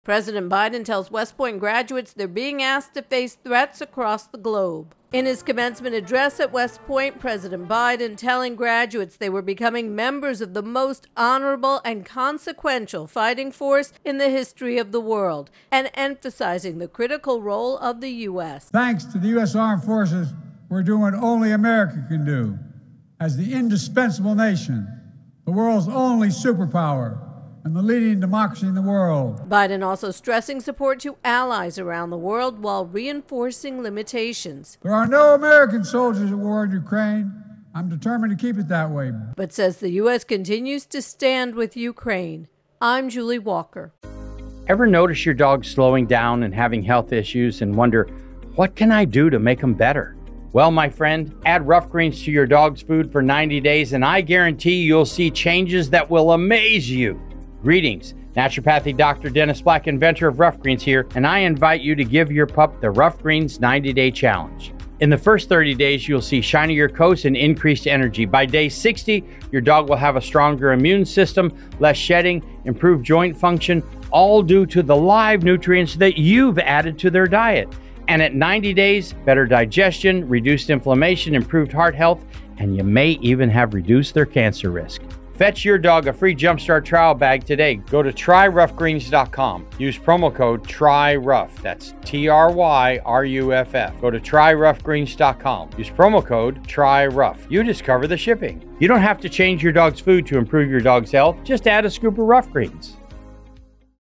reports President Biden delivers the commencement speech at West Point on Saturday.